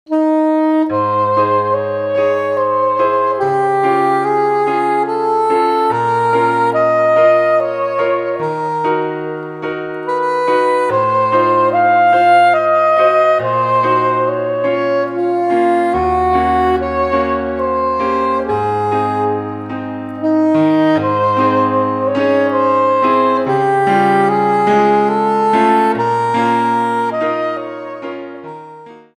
Saxophone en Sib et Piano